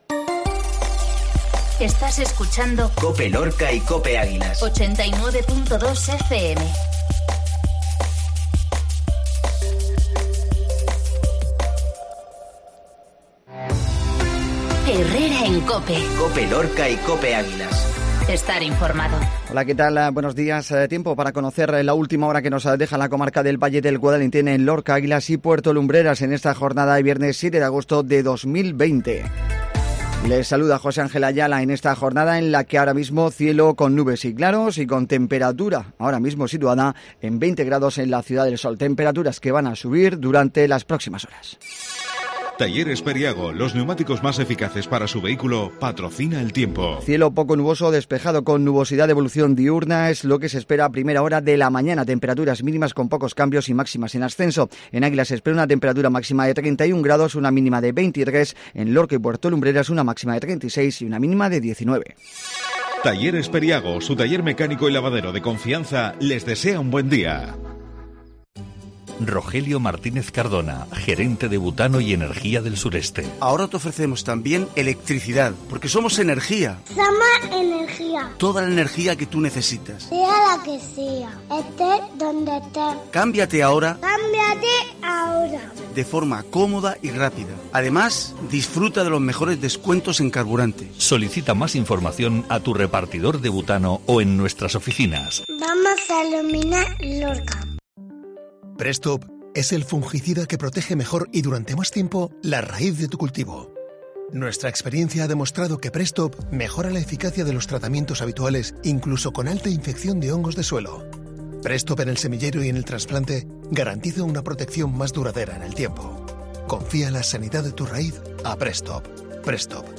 INFORMATIVO MATINAL VIERNES